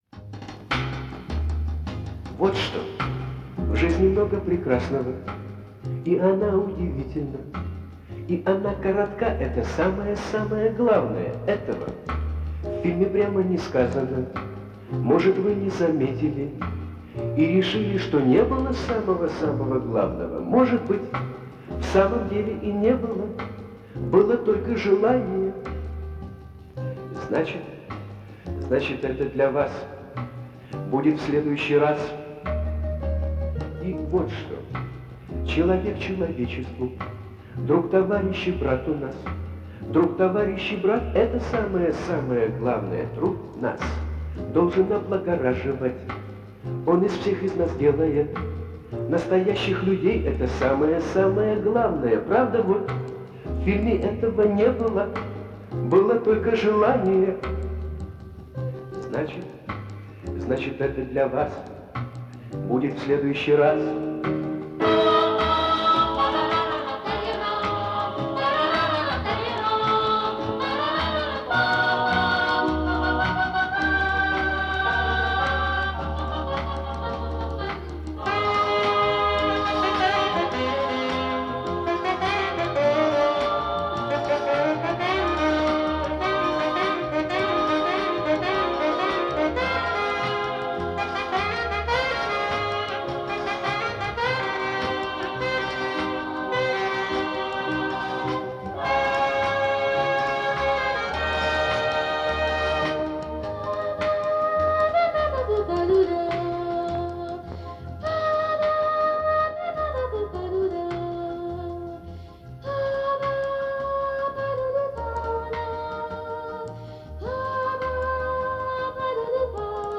Скажу сразу - в подборке качество очень различное.